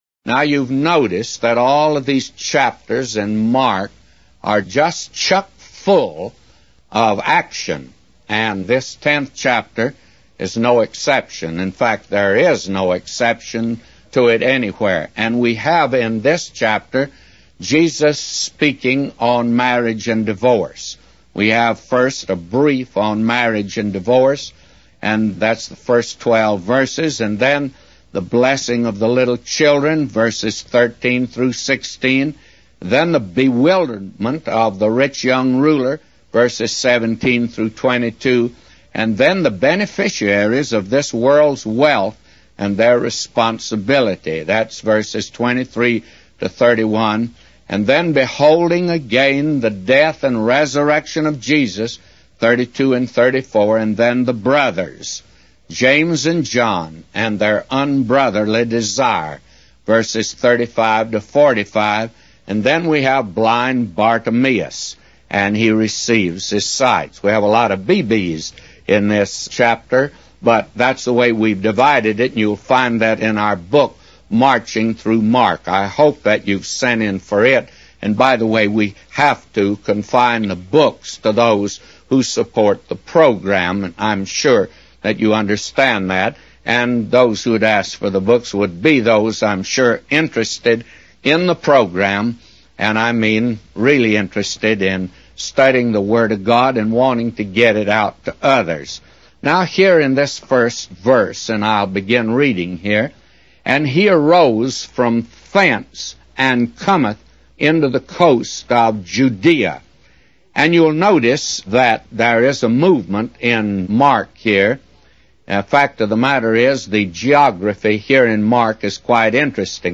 A Commentary By J Vernon MCgee For Mark 10:1-31